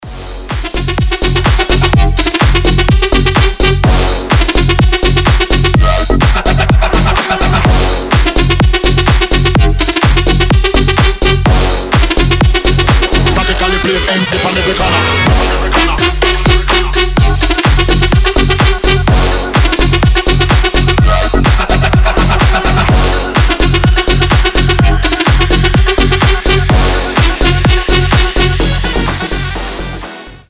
• Качество: 320, Stereo
громкие
жесткие
мощные басы
Bass House
взрывные
electro house